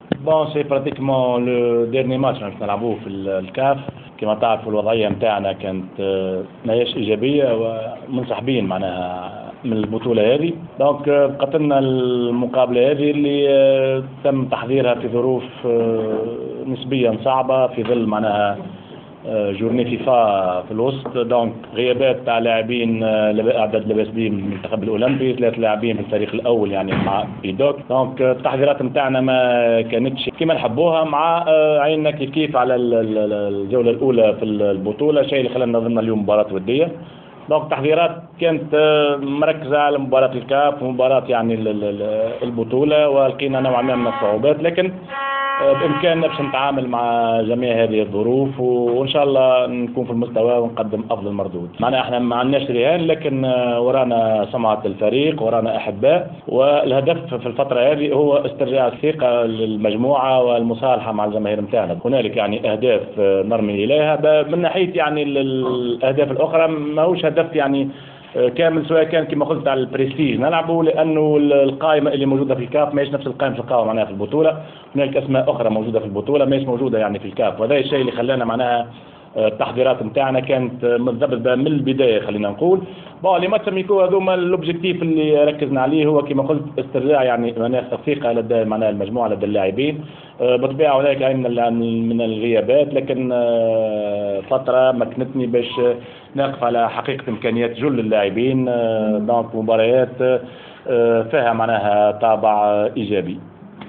عقد مدرب الترجي الرياضي التونسي عمار السويح ندوة صحفية للحديث حول اخر مستجدات الفريق قبل مواجهة النجم الرياضي الساحلي يوم السبت 12 سبتمبر 2015 بداية من الساعة 20:00 في إطار الجولة السادسة والأخيرة من دور المجموعتين لكأس الاتحاد الإفريقي.